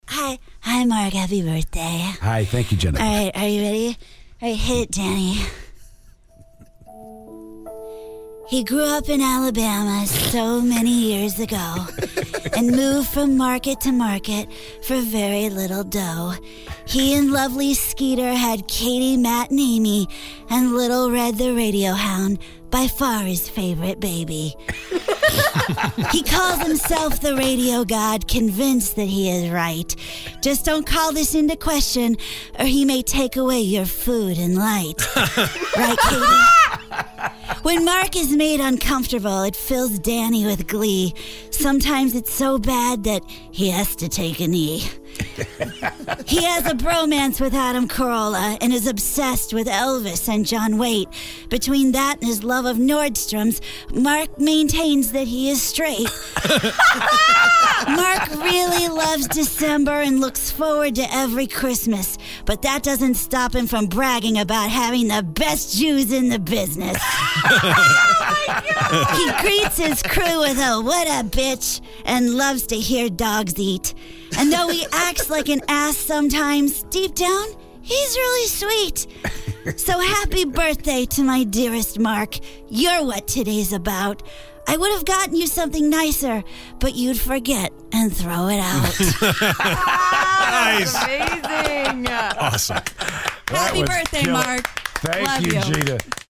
Jennifer Tilly reads a birthday Poem